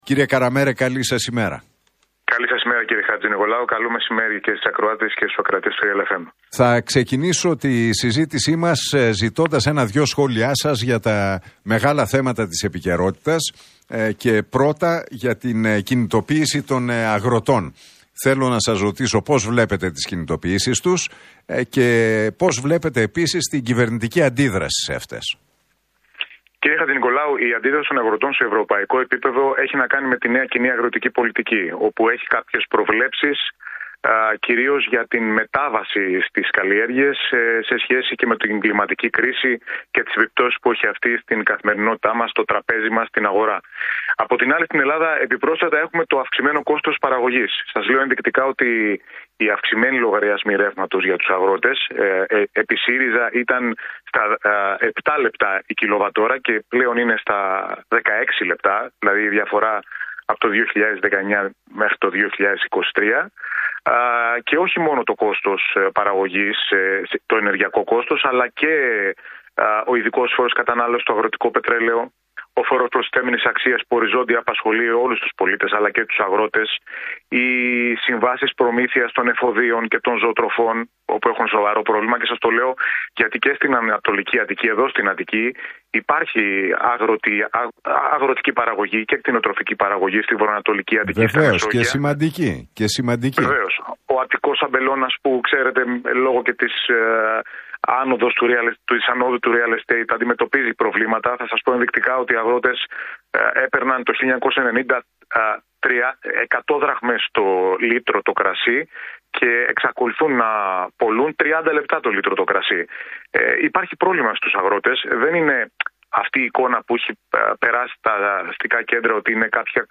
Για τις κινητοποιήσεις των αγροτών, τις καταλήψεις στα Πανεπιστήμια και τα F-35 μίλησε μεταξύ άλλων ο Γιώργος Καραμέρος στον Realfm 97,8 και την εκπομπή του Νίκου Χατζηνικολάου.